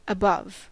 Ääntäminen
Vaihtoehtoiset kirjoitusmuodot (vanhentunut) abun Ääntäminen US : IPA : /ə.ˈbʌv/ Lyhenteet abv.